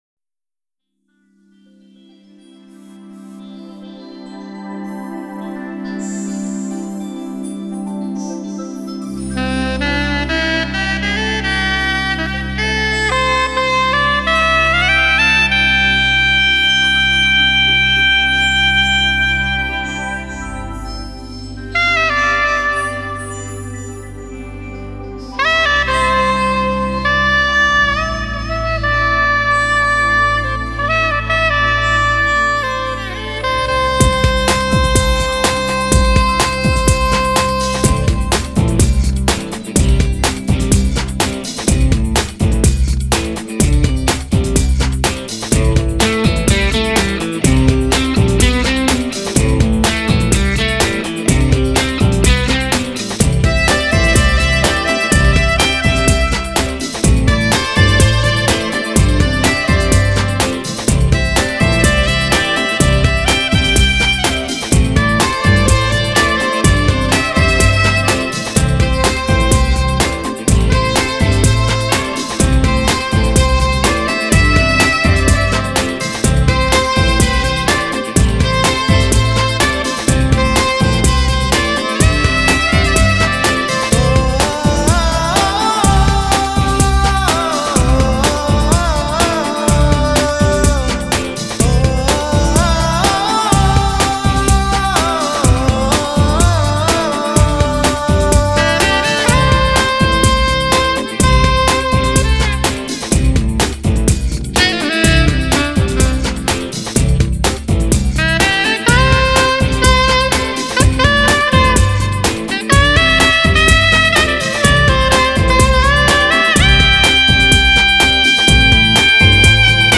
Lyricist: Instrumental